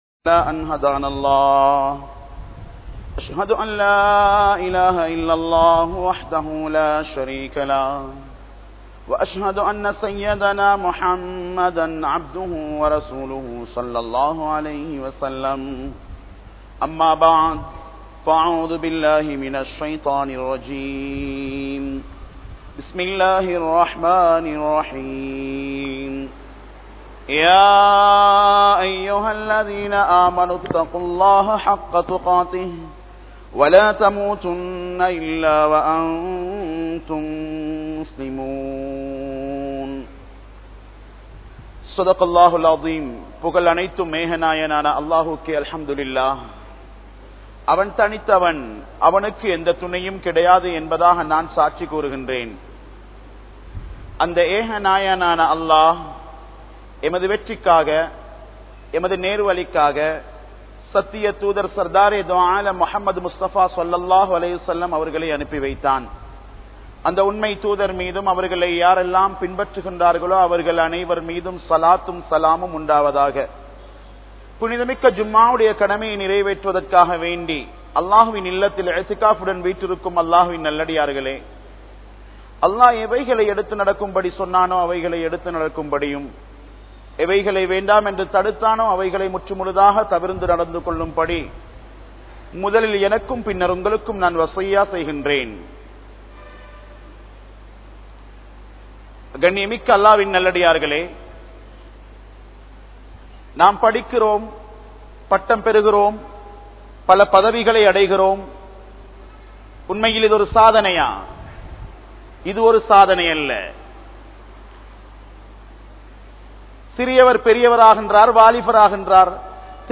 Ulaha Vaalkai Saathanaiya? Soathanaiya? (உலக வாழ்க்கை சாதனையா? சோதனையா?) | Audio Bayans | All Ceylon Muslim Youth Community | Addalaichenai